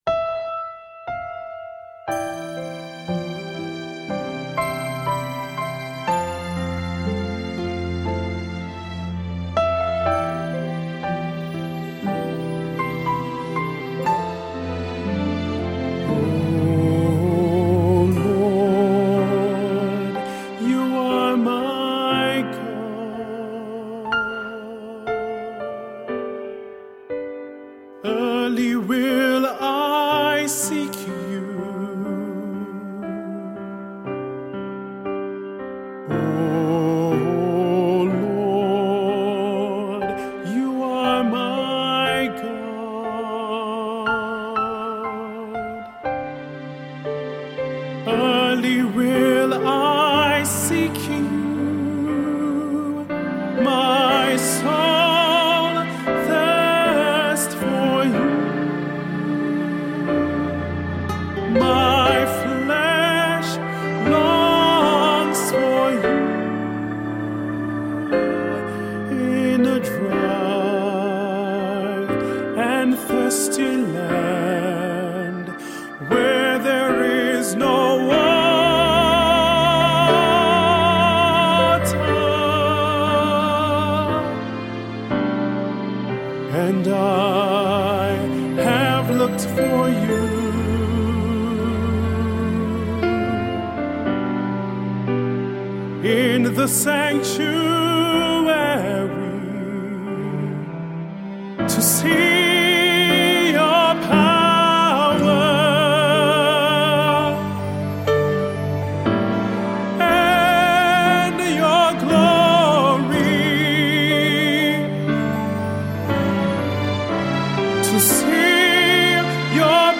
Gospel (praise & worship)